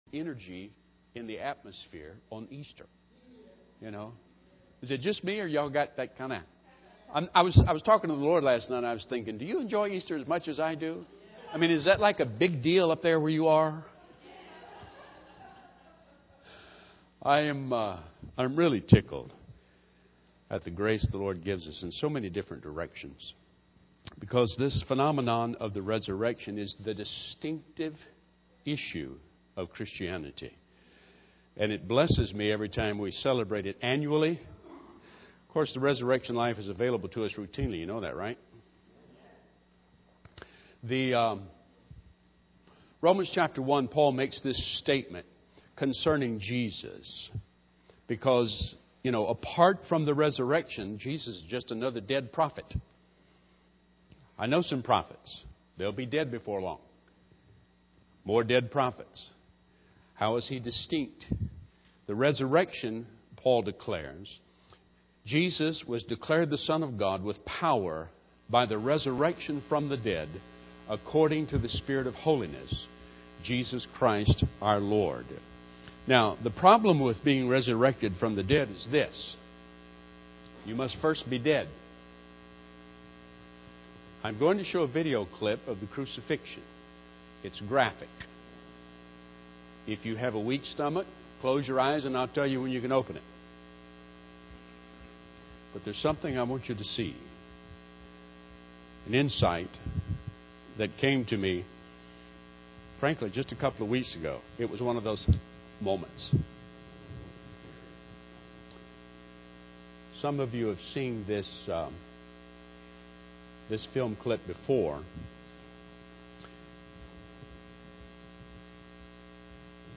Easter sermon